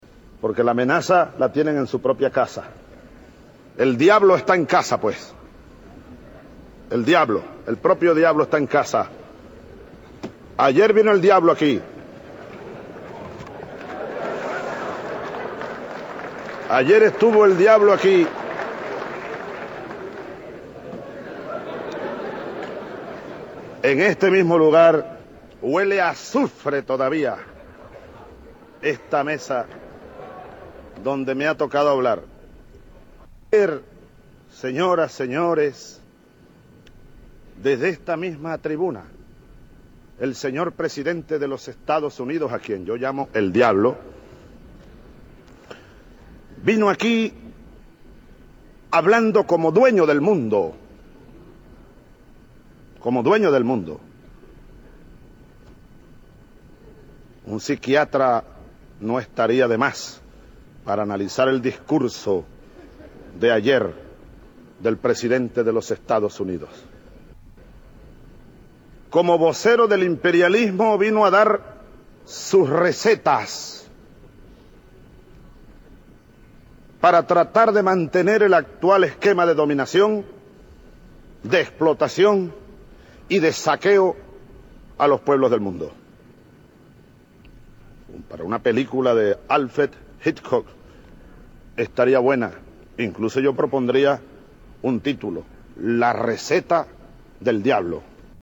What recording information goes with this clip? Calls Bush the Devil At UN (Spanish)